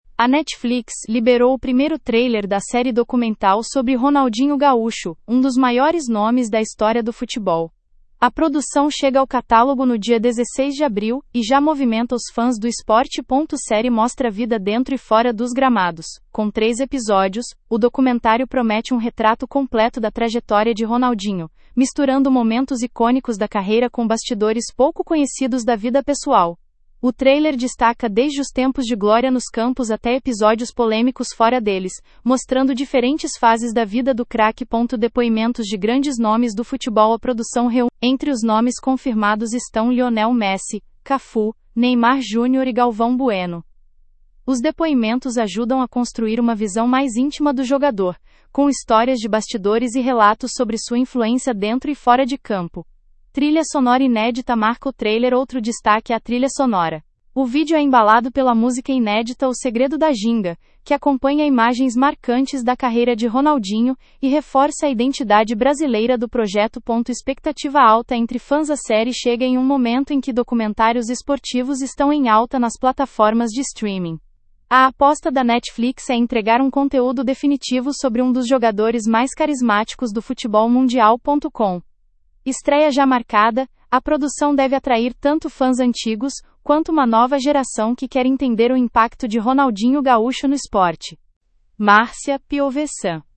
Os depoimentos ajudam a construir uma visão mais íntima do jogador, com histórias de bastidores e relatos sobre sua influência dentro e fora de campo.
Trilha sonora inédita marca o trailer
O vídeo é embalado pela música inédita “O Segredo da Ginga”, que acompanha imagens marcantes da carreira de Ronaldinho e reforça a identidade brasileira do projeto.